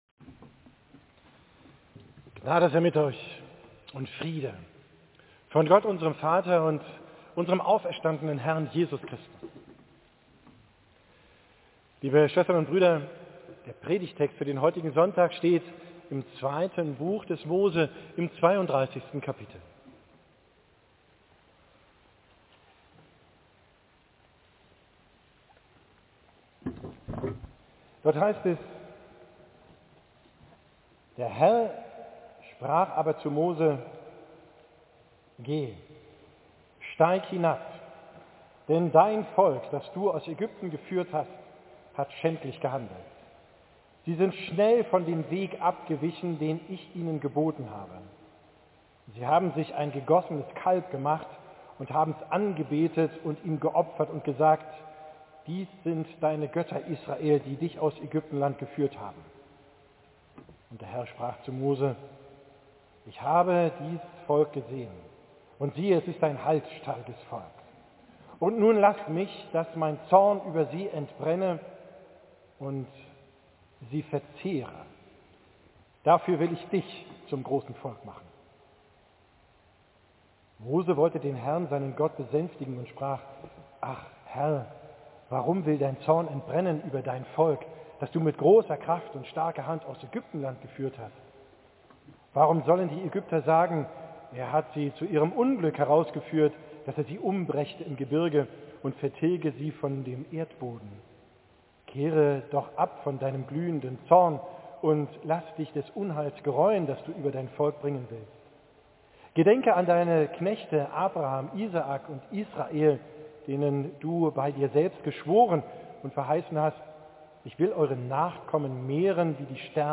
Predigt vom Sonntag Rogate, 5.